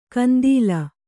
♪ kandīla